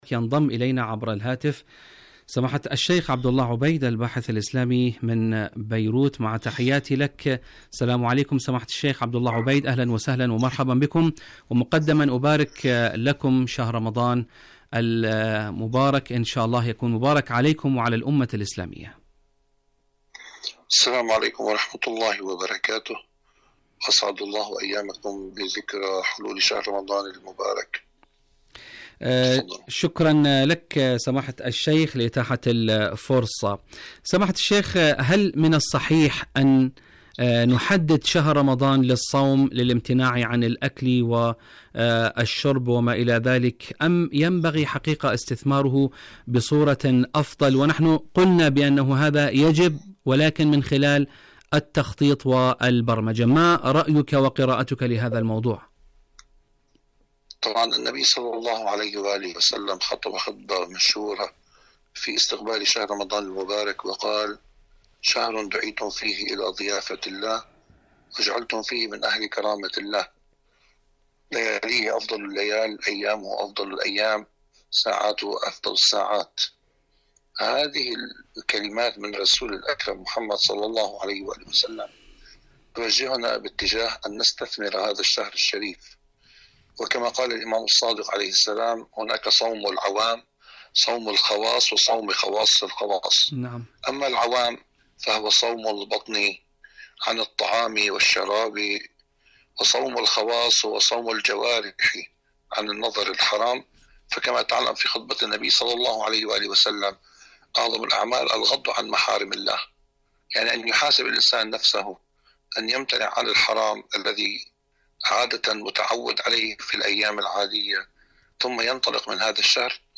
مقابلات برامج إذاعة طهران العربية مقابلات إذاعية دنيا الشباب الشباب ماذا تخطط لشهر رمضان؟